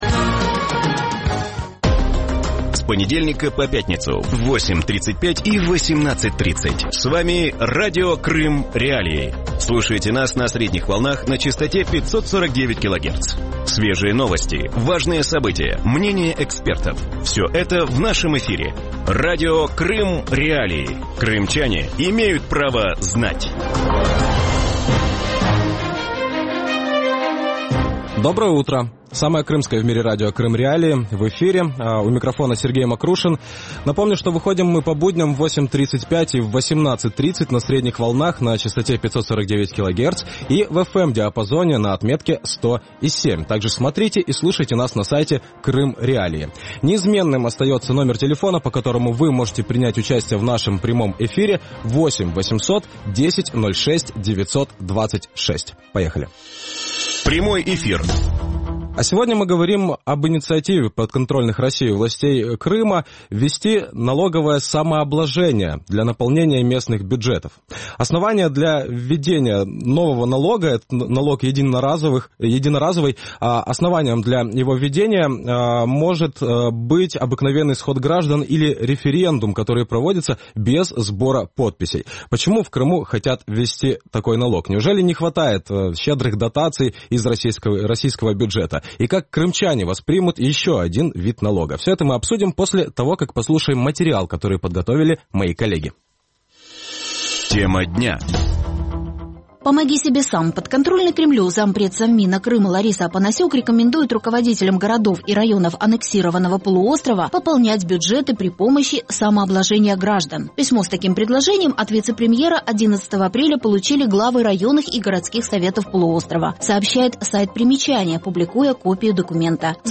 Радио Крым.Реалии выходит по будням в 8:35 и 18:30 на средних волнах АМ – частота 549 кГц, в FM-диапазоне на частоте 100.7 МГц, а также на сайте Крым.Реалии. Крымчане могут бесплатно звонить в эфир по телефону 8 800